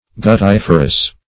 guttiferous - definition of guttiferous - synonyms, pronunciation, spelling from Free Dictionary
Search Result for " guttiferous" : The Collaborative International Dictionary of English v.0.48: Guttiferous \Gut*tif"er*ous\, a. (Bot.)